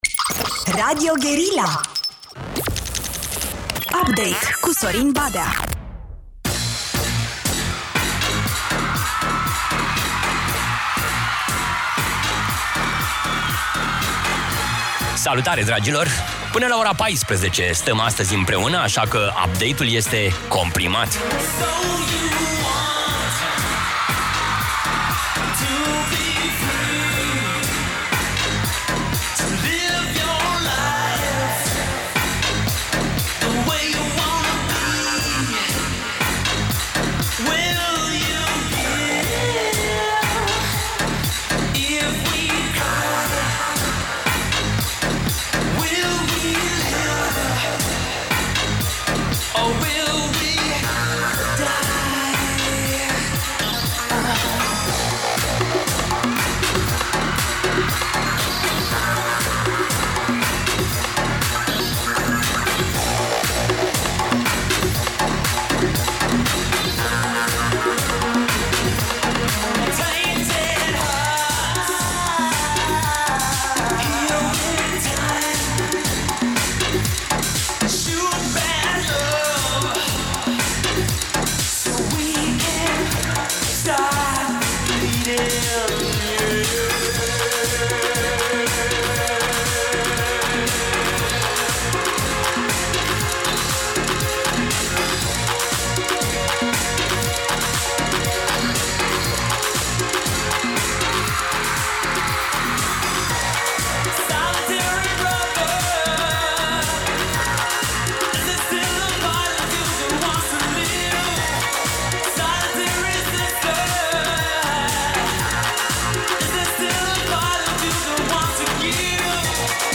Invitați, discuții și muzică, adică un meniu obișnuit.